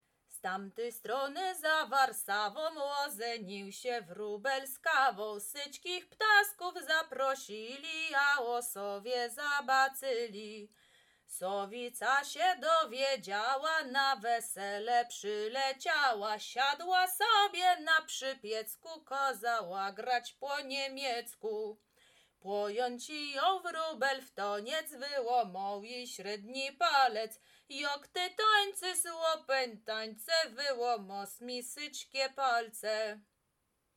Krakowskie
Kolęda